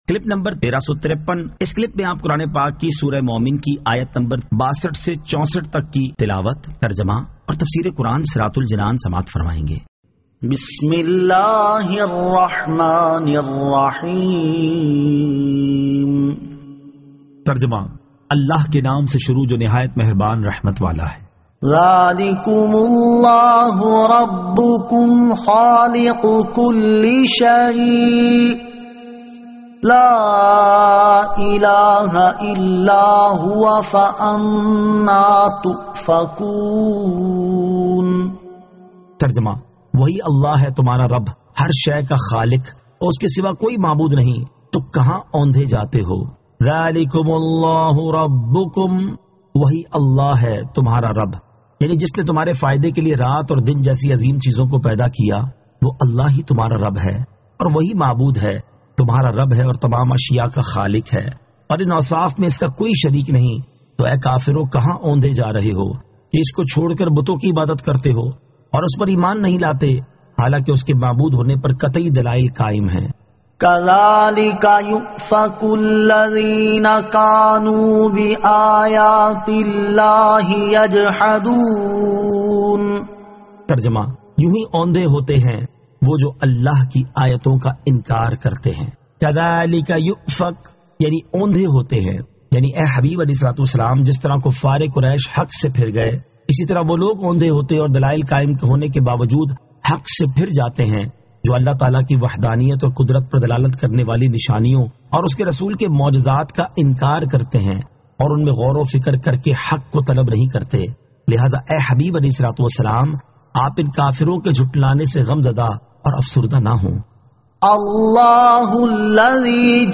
Surah Al-Mu'min 62 To 64 Tilawat , Tarjama , Tafseer